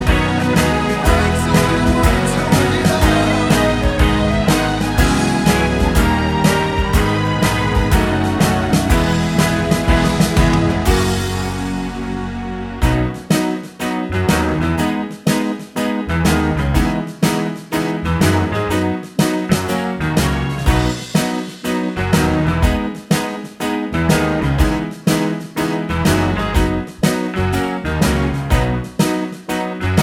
Two Semitones Down Jazz / Swing 4:01 Buy £1.50